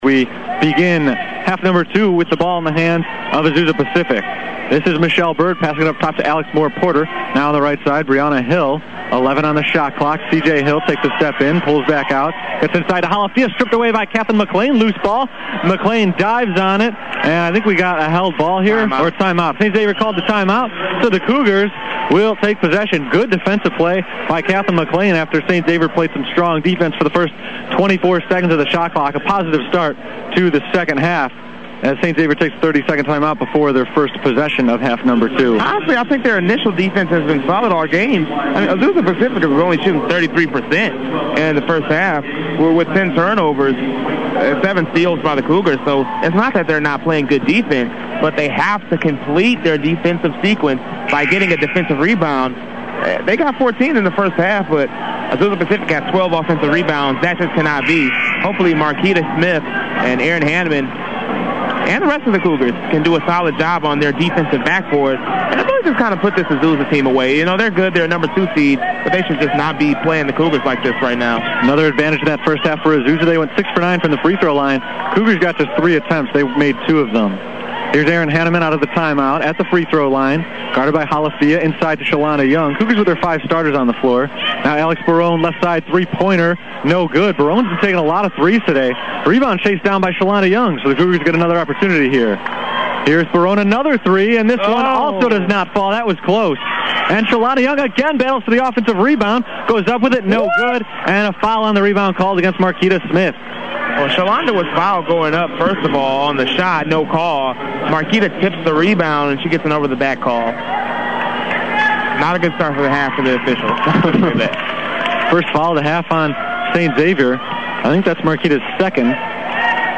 The last game I ever called in college, this one pits Saint Xavier’s women against Azusa Pacific in the national quarterfinals on March 20, 2010.
Basketball PBP 3.mp3